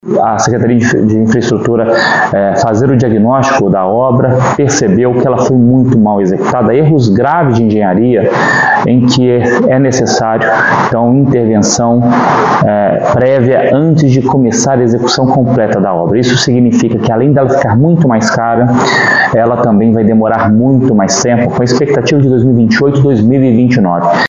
Conforme o secretário de Estado de Saúde, Fábio Baccheretti, a obra precisaria ser refeita.